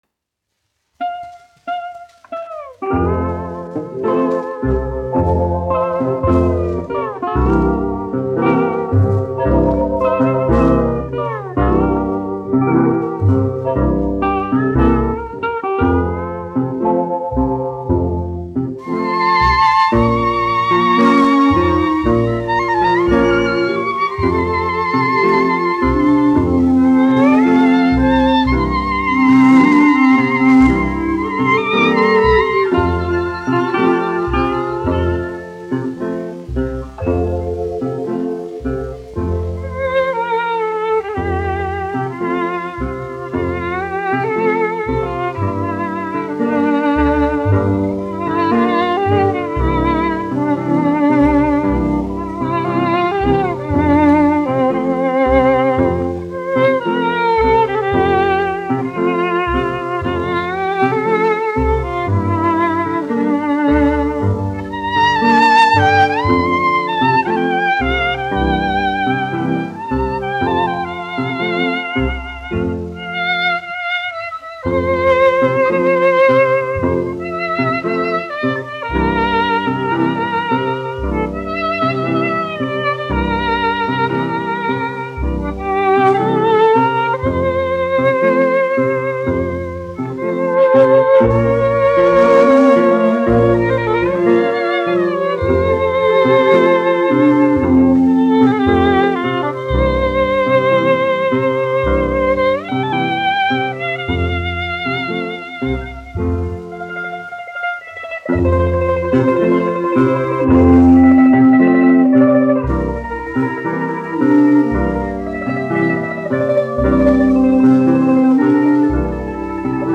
1 skpl. : analogs, 78 apgr/min, mono ; 25 cm
Dziesmas, neapoliešu
Populārā instrumentālā mūzika
Skaņuplate
Latvijas vēsturiskie šellaka skaņuplašu ieraksti (Kolekcija)